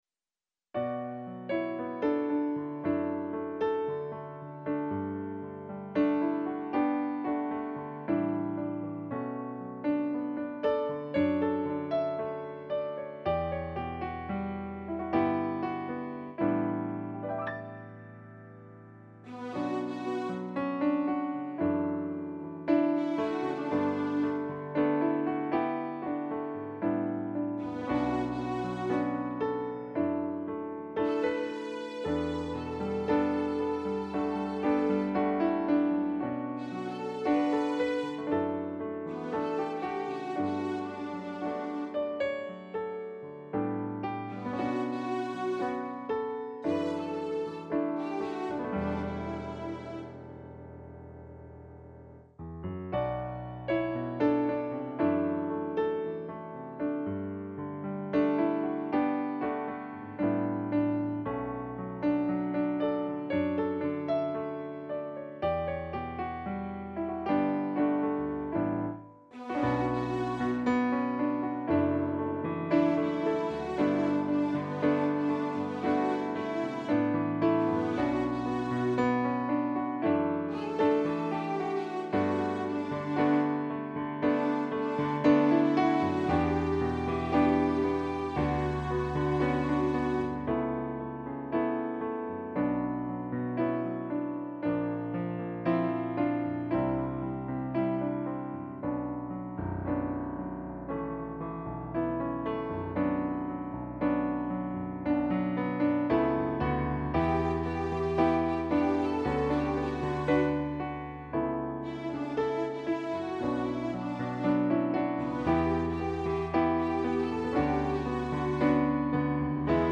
Trzecia-rano_podklad-z-linia.mp3